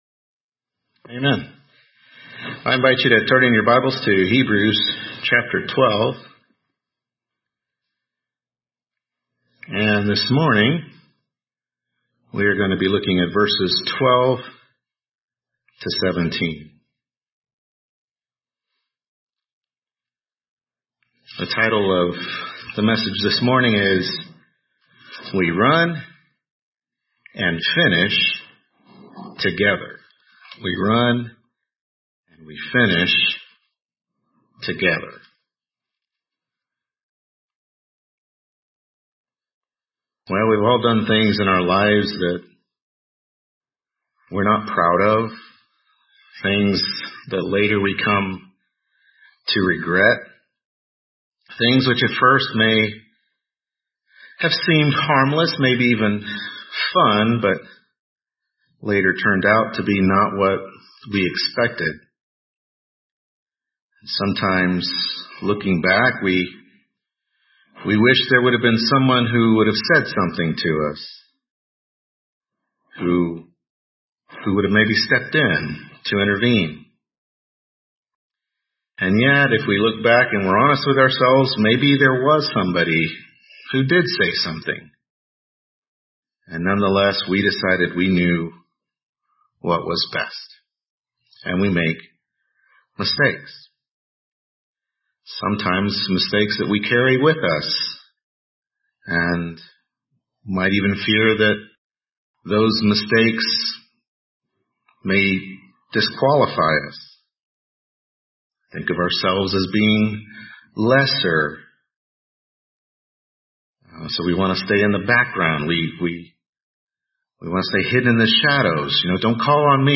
Hebrews 12:12-17 Service Type: Morning Worship Service Hebrews 12:12-17 We Run and We Finish Together « Hebrews 12:4-11.